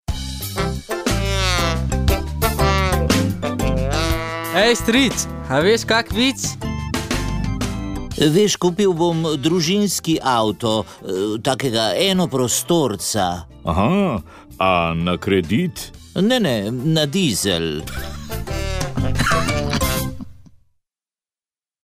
Vremenska napoved 25. september 2022